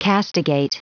Prononciation du mot castigate en anglais (fichier audio)
Prononciation du mot : castigate